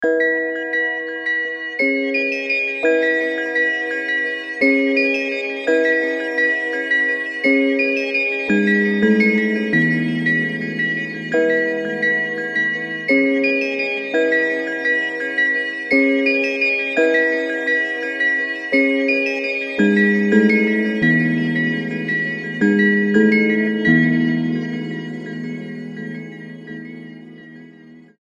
мелодичные
спокойные
без слов
колокольчики
звонкие
электронные